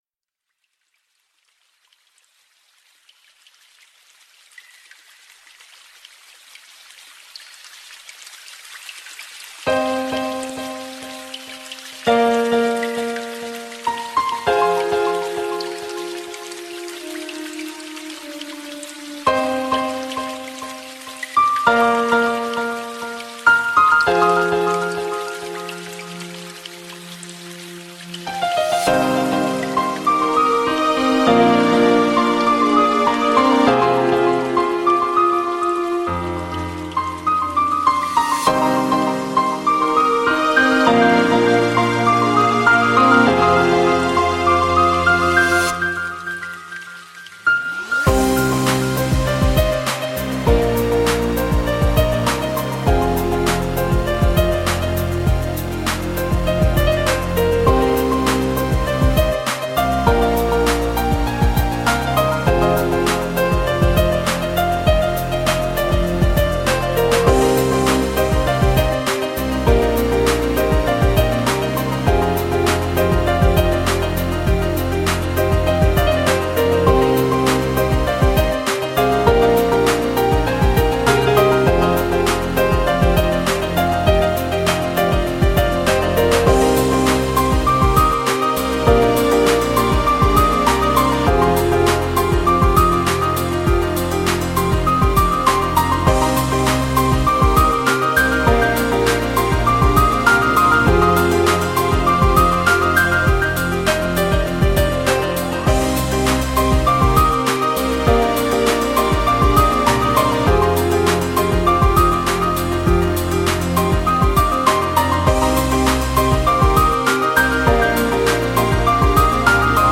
Žánr: Jazz/Blues
Trošku symfonický a orchestrálny experiment.